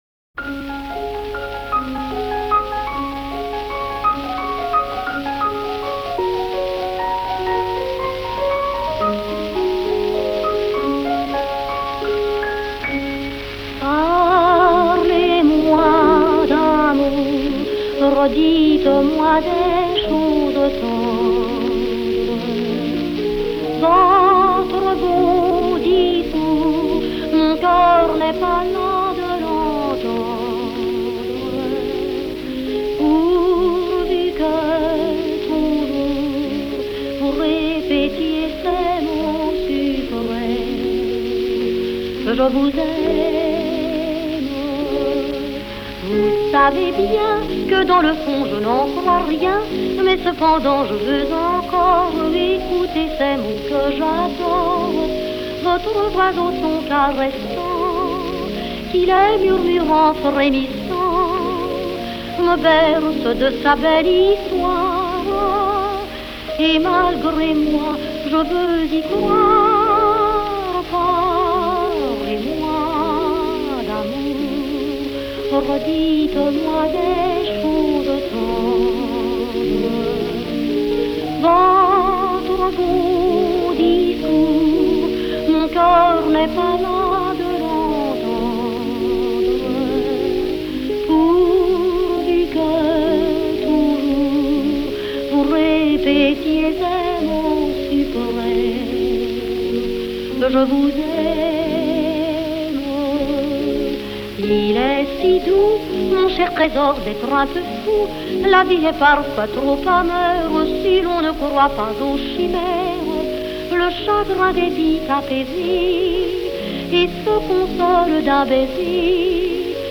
Французская эстрада